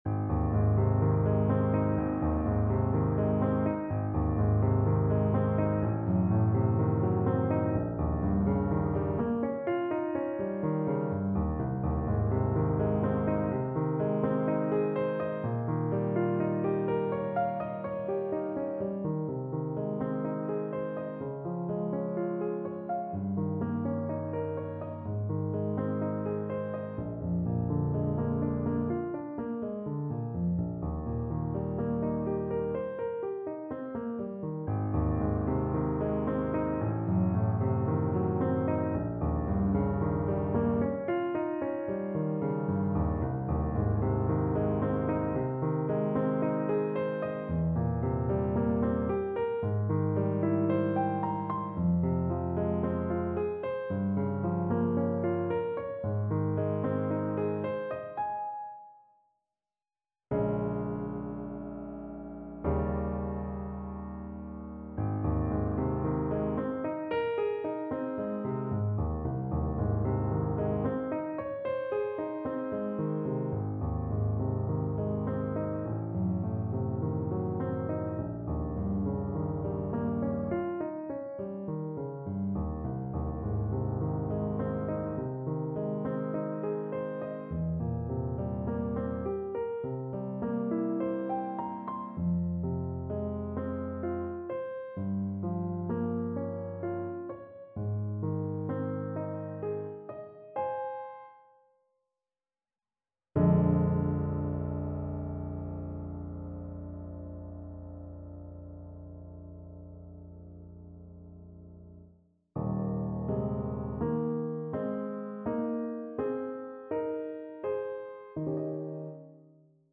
Clarinet  (View more Easy Clarinet Music)
Classical (View more Classical Clarinet Music)